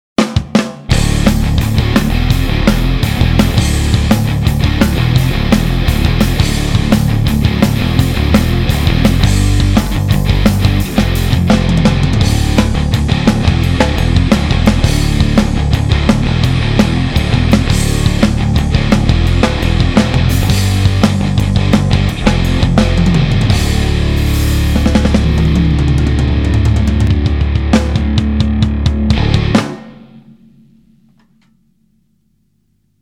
Drop D Soundcheck
soooo... habe mich nochmal rangewagt, den Verzerrer bis hinten aufgerissen, ein anderes Mikro genommen und mich beim Schlagzeugspielen mal etwas mehr konzentriert...
Habe hier jetzt die Höhen (bzw. Hochmitten) bei den Gitarren angehoben und eine Mittenabsenkung rausgenommen... hört nochmal rein :)